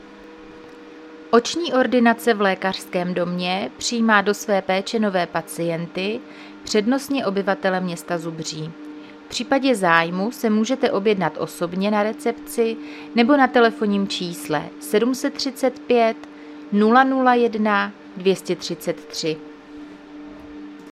Záznam hlášení místního rozhlasu 10.4.2026